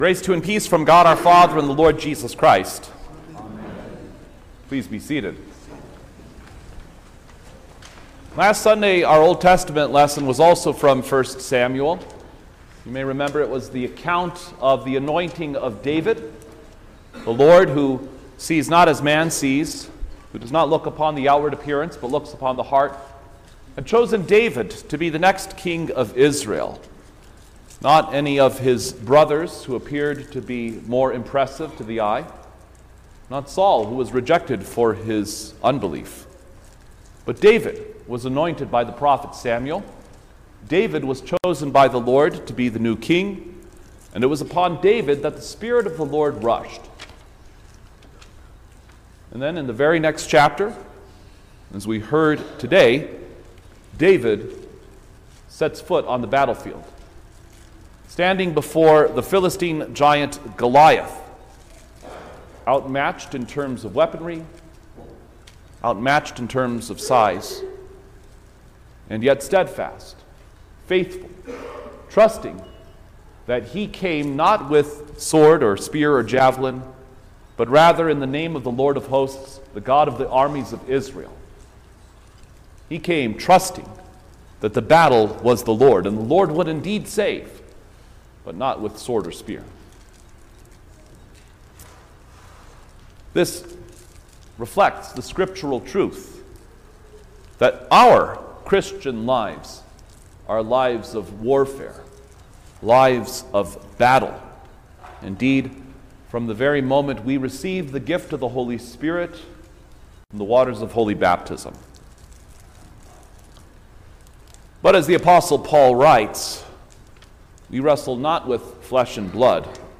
March-9_2025_First-Sunday-in-Lent_Sermon-Stereo.mp3